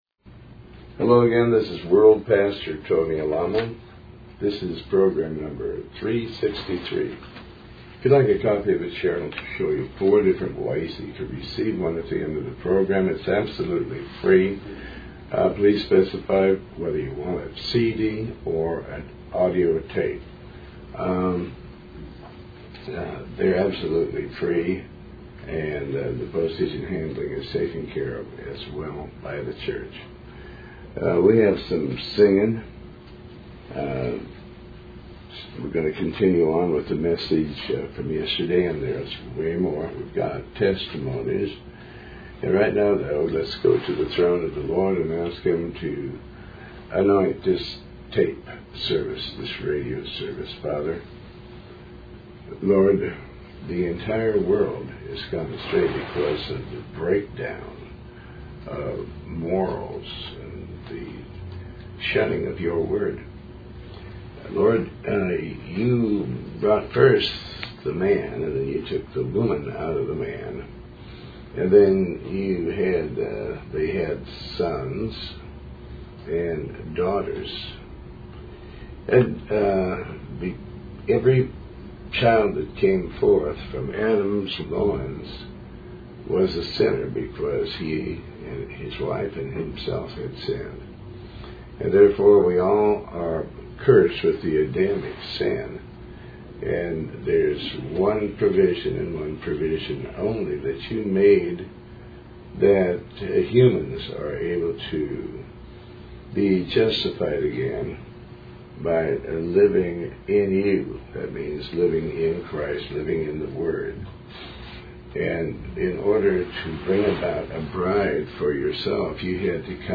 Talk Show Episode, Audio Podcast, Tony Alamo and Program363 on , show guests , about Tony Alamo with Tony Alamo World Wide Ministries, categorized as Health & Lifestyle,History,Love & Relationships,Philosophy,Psychology,Christianity,Inspirational,Motivational,Society and Culture